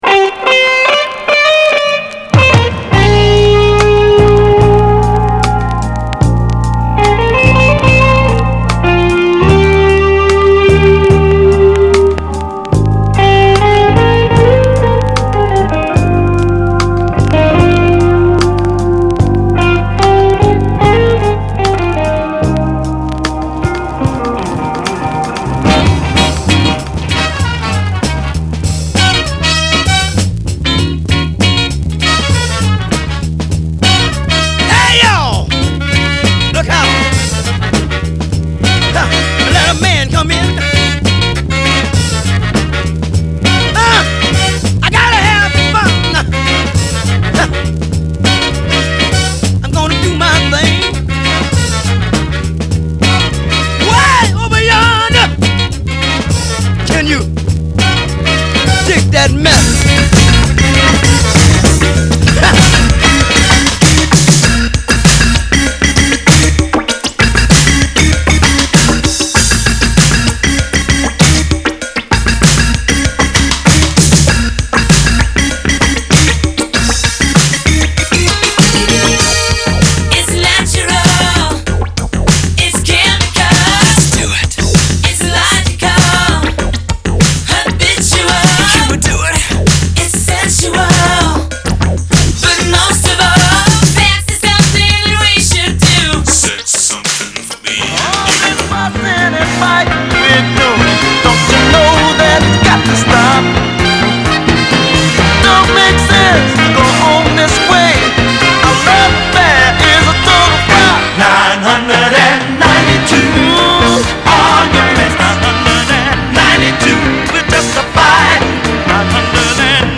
搖滾、西洋流行歌曲